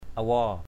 /a-wɔ:/ (đg.) khiếu nại. to complain, appeal. harak awaow hrK a_w<| đơn khiếu nại.